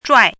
“拽”读音
zhuài
zhuài.mp3